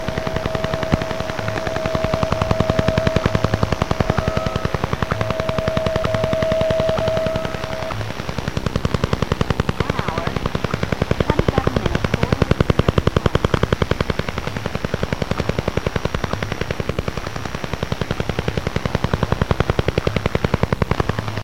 Вы можете прослушать и скачать записи работы станции: гул генераторов, щелчки антенн и другие технические шумы.
Звук радиолокационной станции Дуга в Чернобыле, русский дятел, запись от 2 ноября 1984 года